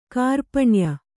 ♪ kārpaṇya